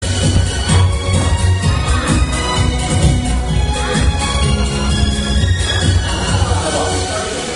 一首儿童歌曲只有前奏，急求高人告知歌名，多谢！！！